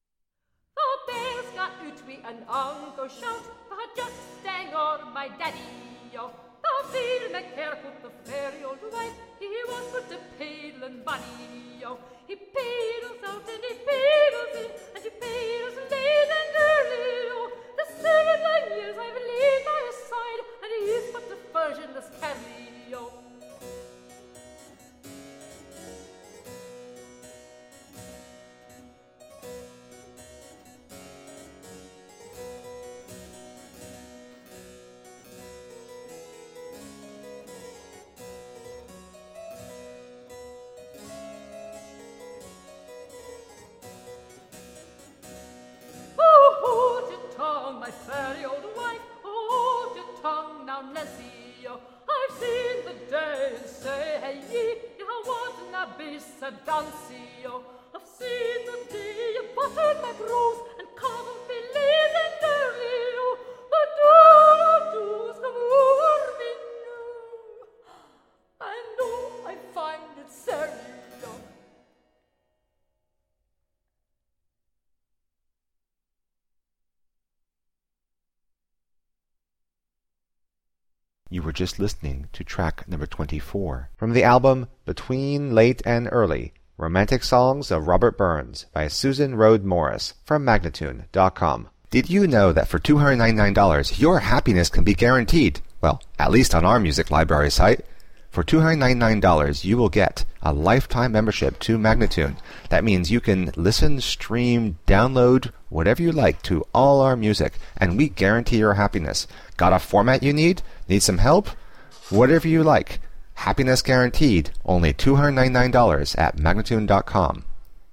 Tagged as: Classical, Folk, Choral, Celtic